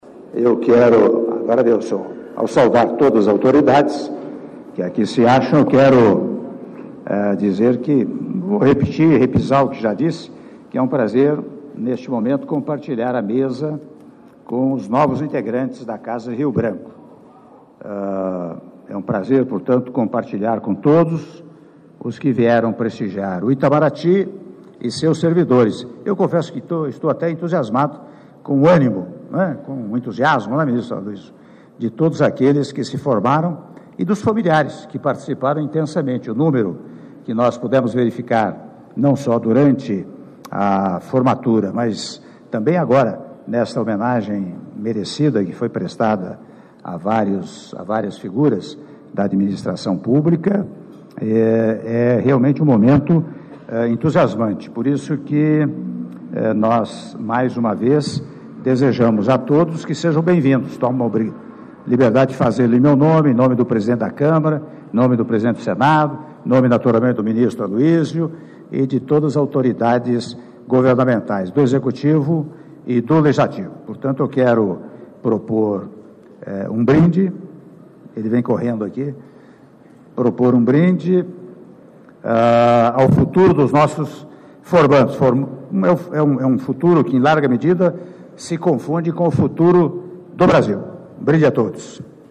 Áudio do brinde do Presidente da República, Michel Temer, durante almoço em homenagem aos formandos do Instituto Rio Branco - (01min32s) - Brasília/DF